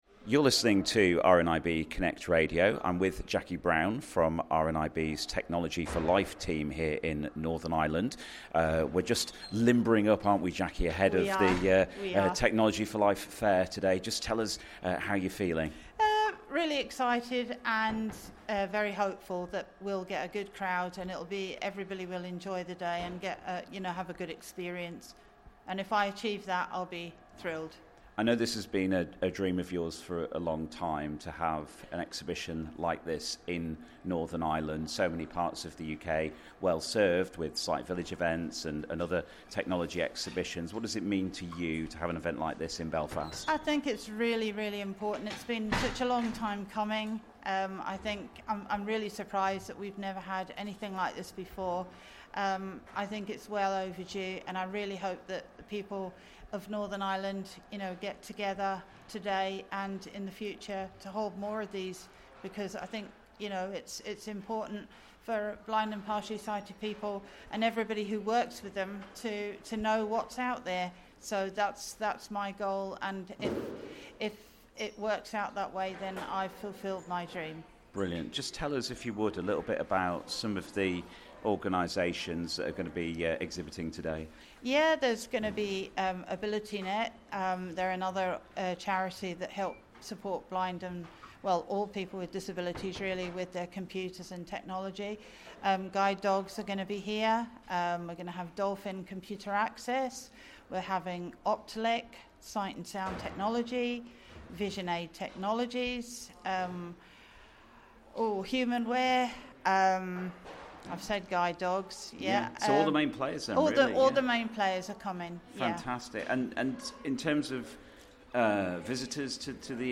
Getting Underway At RNIB's Technology For Life Fair In Belfast